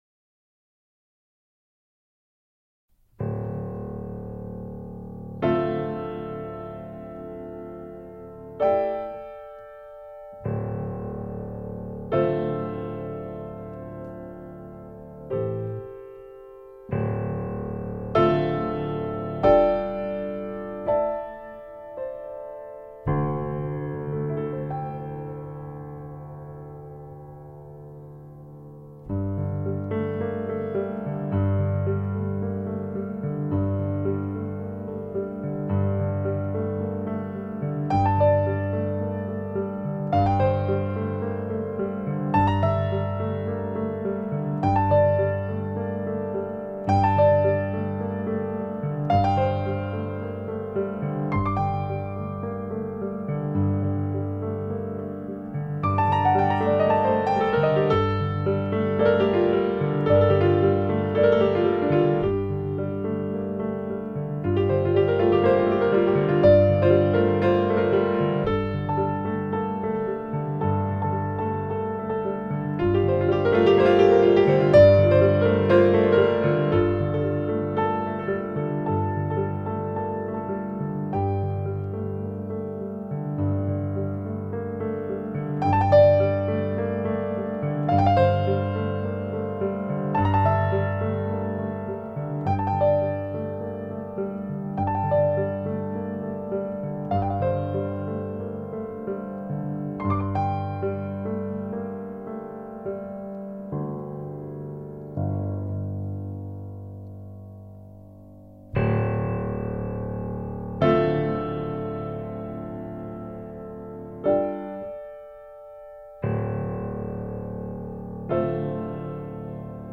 original piano music with a romantic touch